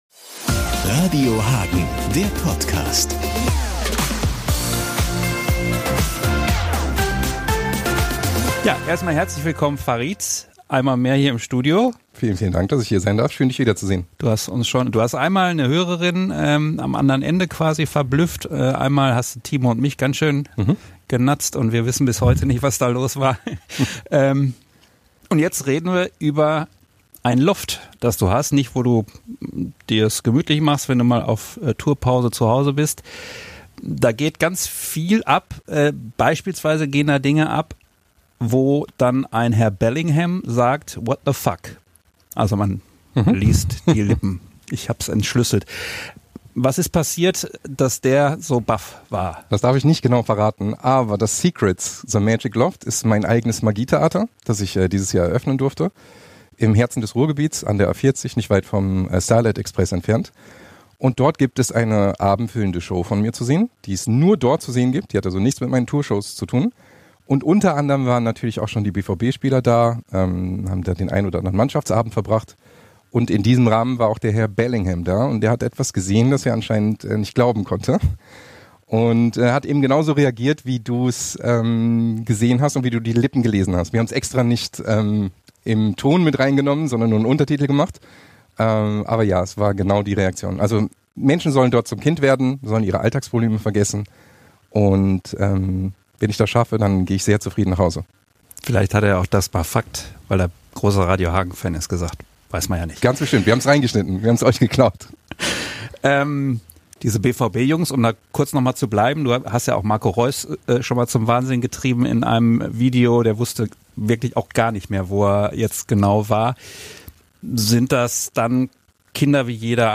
Ein ausführliches Gespräch als Radio Hagen Podcast online!